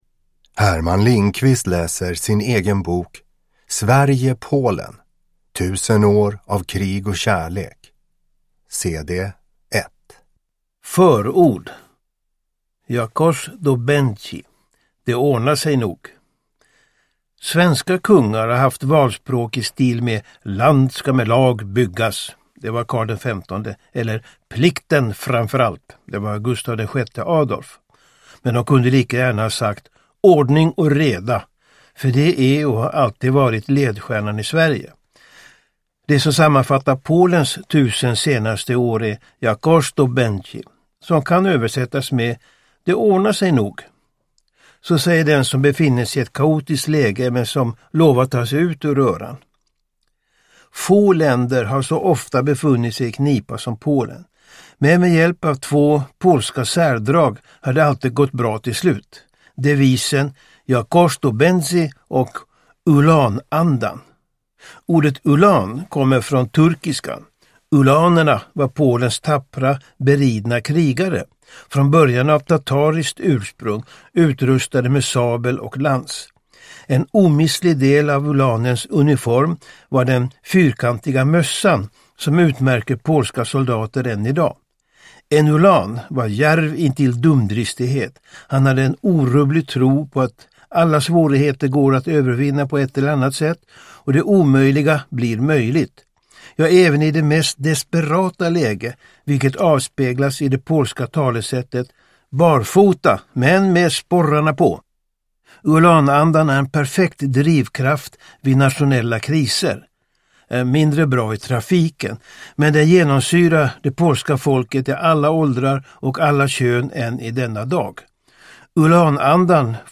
Uppläsare: Herman Lindqvist
Ljudbok